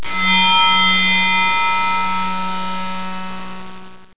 beep20.mp3